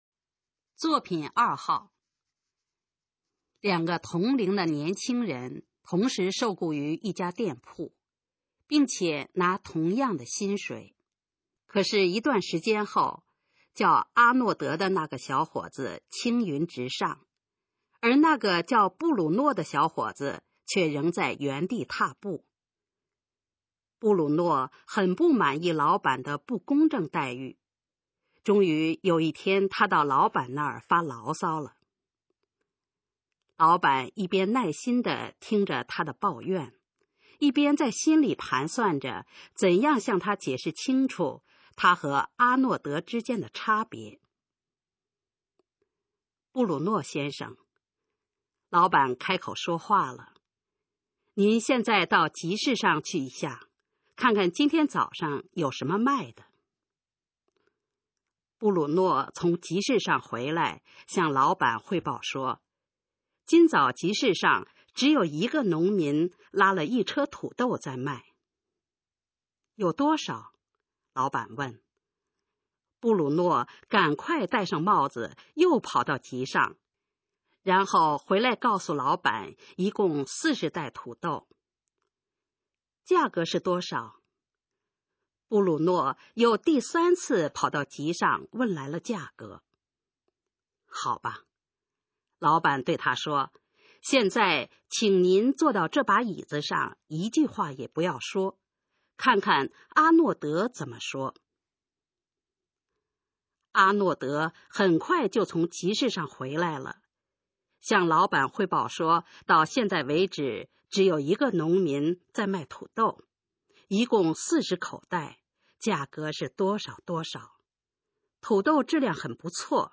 首页 视听 学说普通话 作品朗读（新大纲）
《差别》示范朗读_水平测试（等级考试）用60篇朗读作品范读